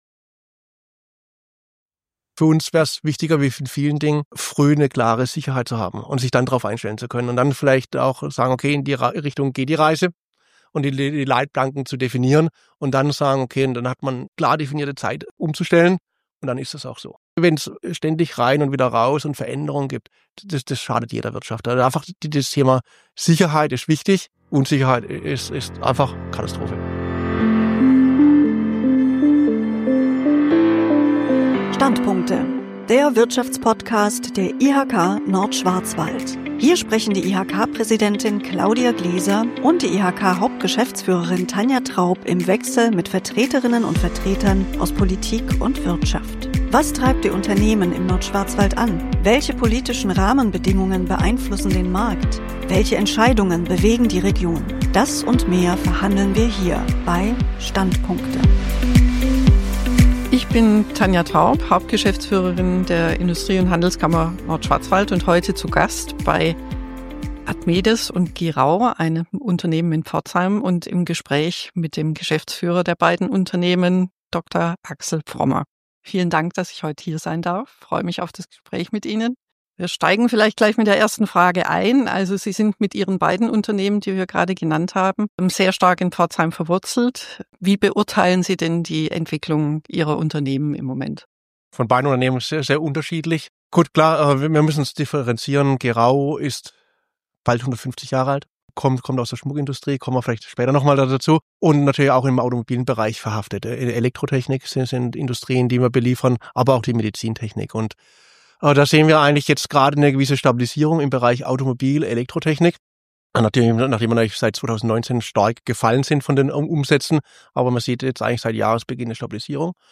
Wie tickt die Wirtschaft im Nordschwarzwald?